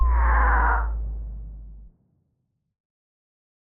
drain.wav